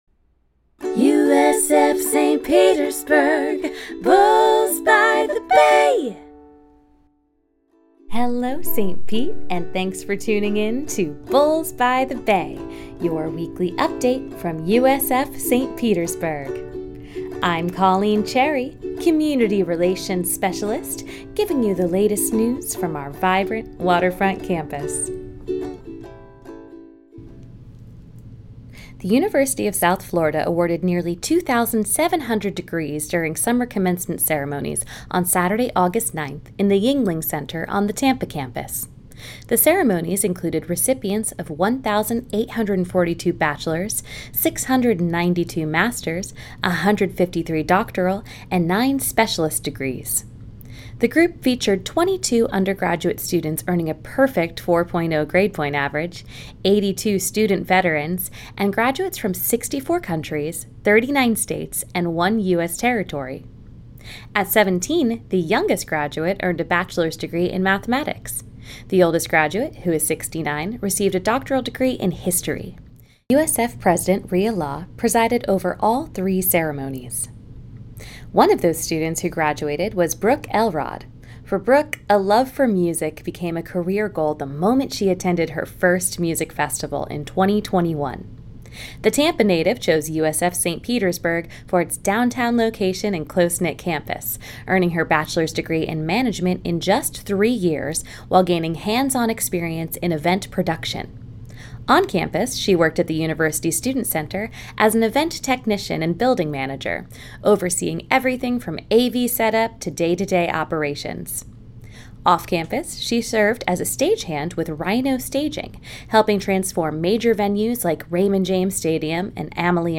🎙 Bulls by the Bay - Weekly News Update